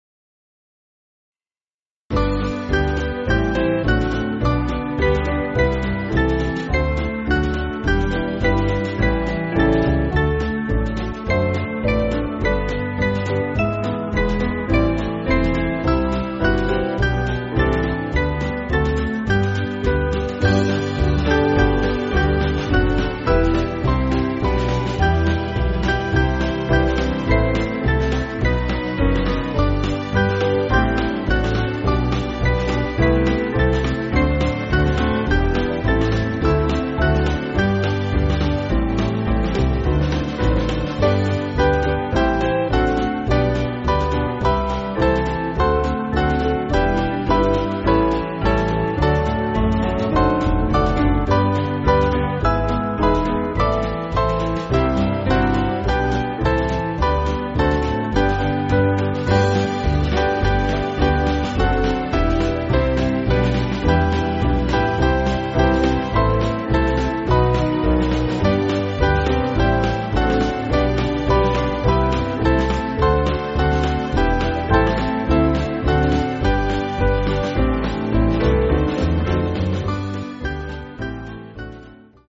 8.7.8.7.D
Small Band